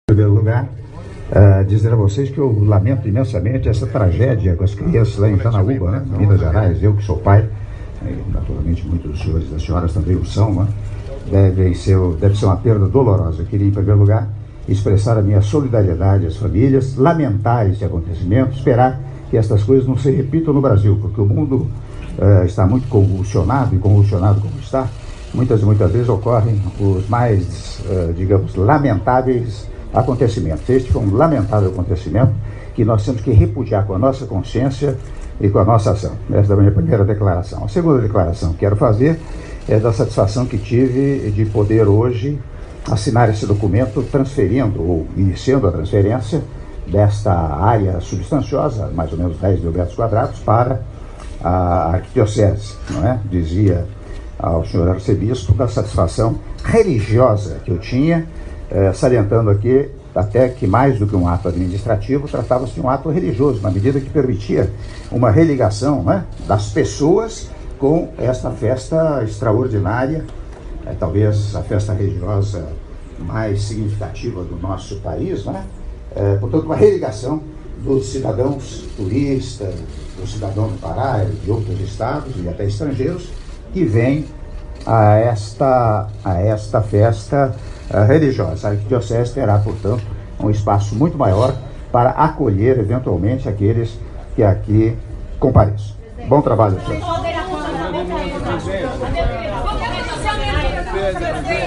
Áudio da entrevista coletiva concedida pelo Presidente da República, Michel Temer, após cerimônia de assinatura de protocolo de intenções sobre destinação social de área a ser utilizada pela Basílica de Nossa Senhora de Nazaré - (01min41s) - Belém/PA — Biblioteca